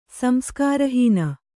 ♪ samskāra hīna